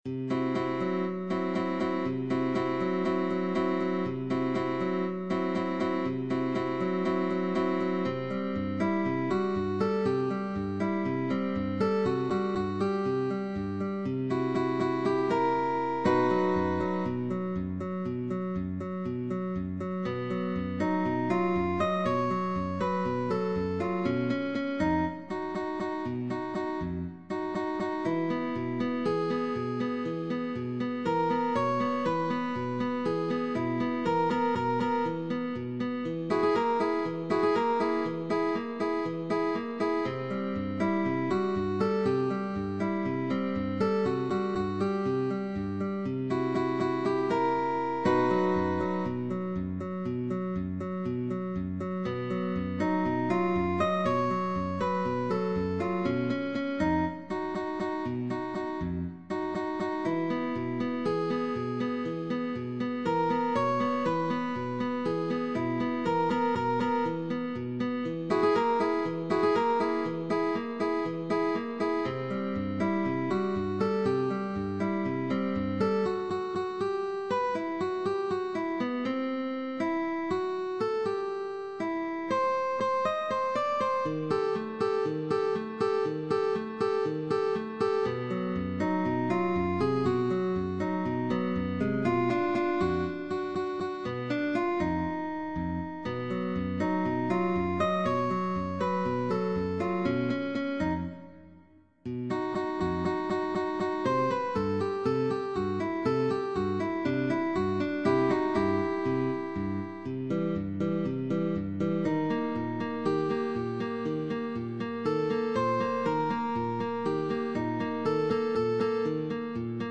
Partitura para dúo de guitarras.
DÚO DE GUITARRAS